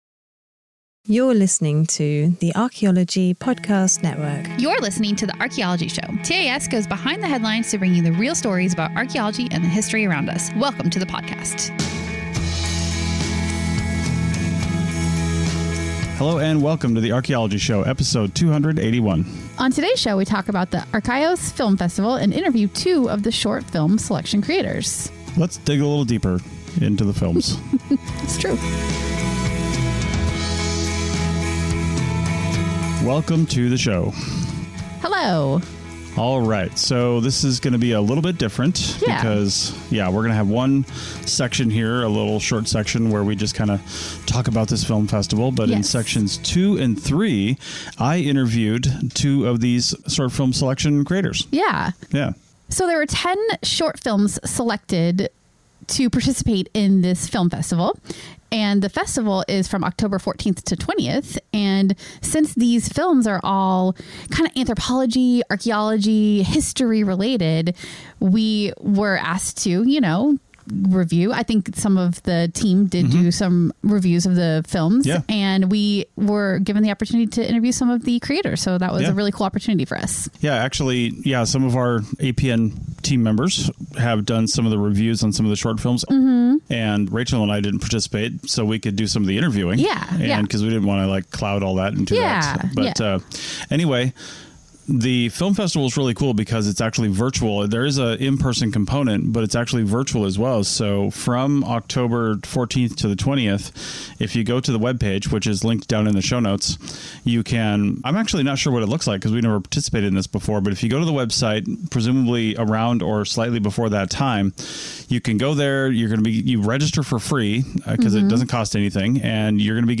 The Arkhaois Film Festival is a free annual event that takes place every fall. It highlights the work of film makers working in the anthropology and archaeology space. This year the Archaeology Podcast Network participated in the jury selection process and we interviewed a few of the film makers.